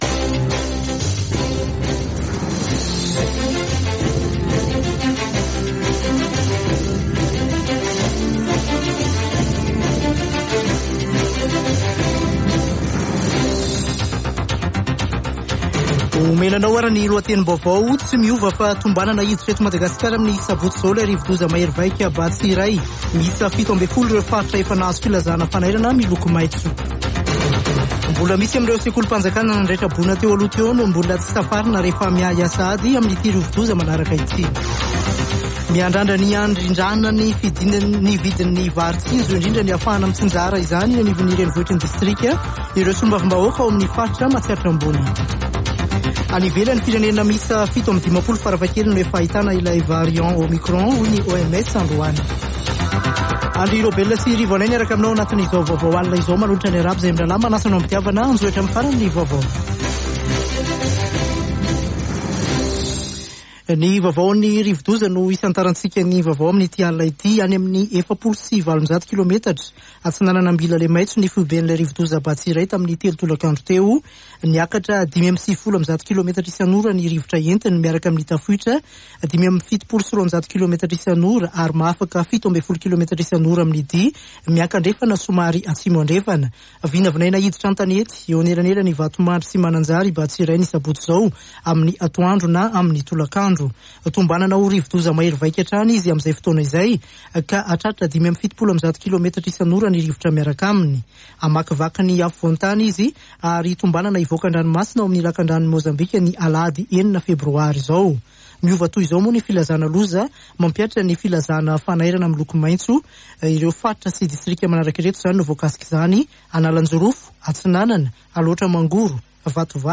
[Vaovao hariva] Alarobia 2 febroary 2022